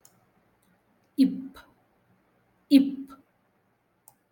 ip